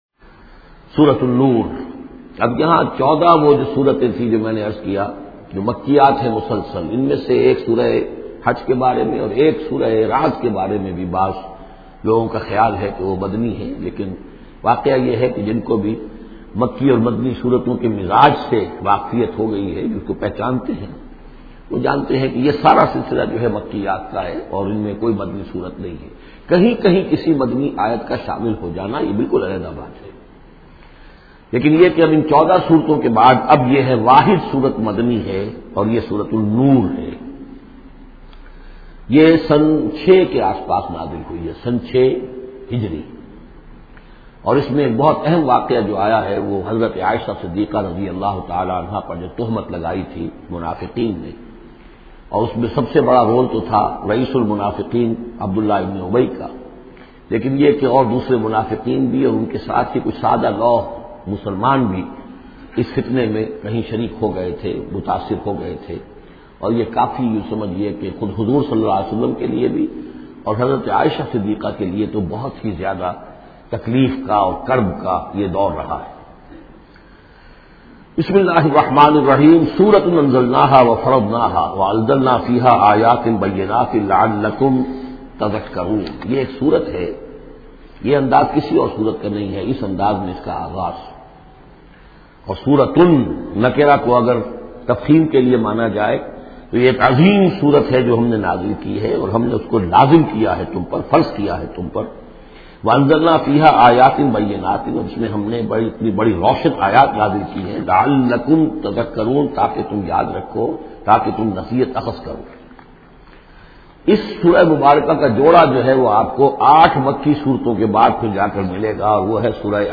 Listen online and download urdu Quran Tafseer of Surah An Nur in the voice of Dr Israr Ahmed.